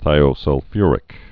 (thīō-sŭl-fyrĭk)